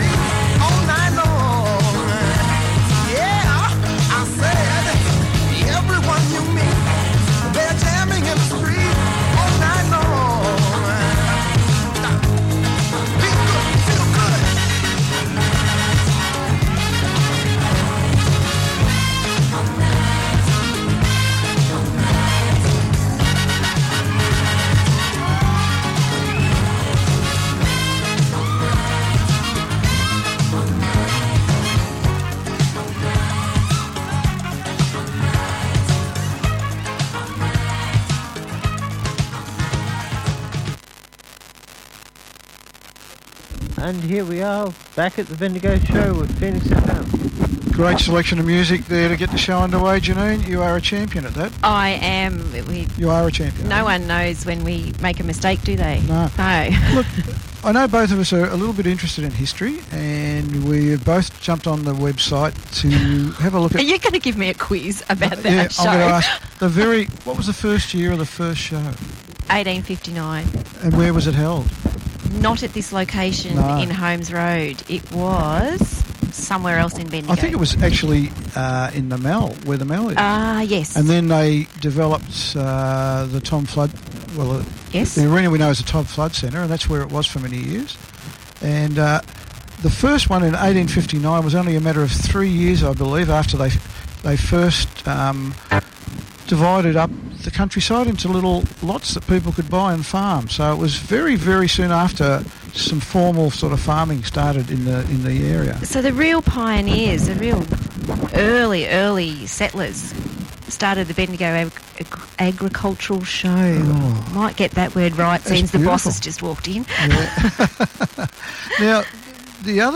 The interview starts at 12 minutes, enjoy!